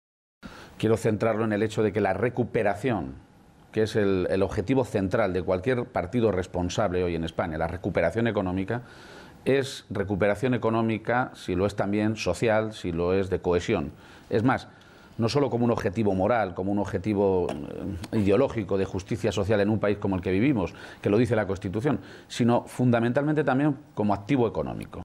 Momento de la entrevista
El candidato a la Presidencia de Castilla-La Mancha y secretario general del PSOE, Emiliano García-Page, ha sido entrevistado esta mañana en Los Desayunos de la Primera de TVE y allí ha insistido en que el principio de acuerdo que ha cerrado con Podemos en la región responde a un objetivo claro y compartido por ambas formaciones políticas: ”El principal objetivo de cualquier Gobierno responsable ahora mismo es la recuperación económica, pero no hay recuperación económica si no hay recuperación y cohesión social”.